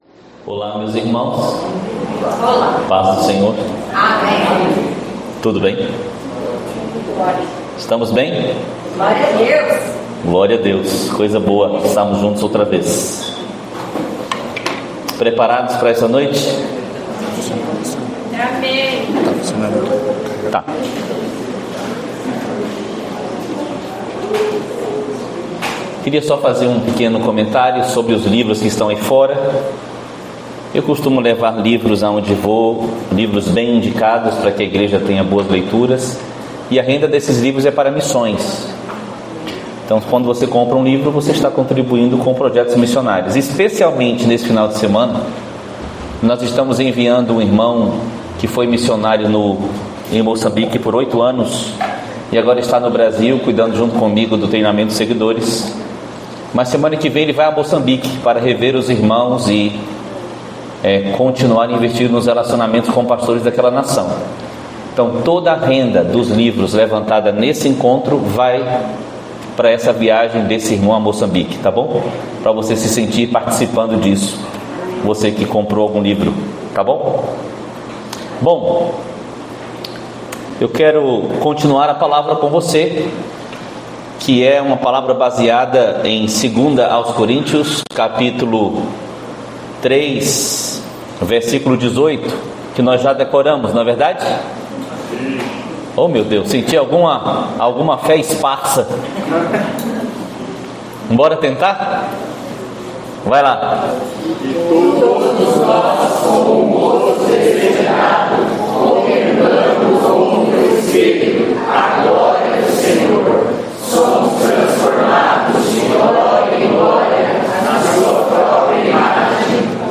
Palavra ministrada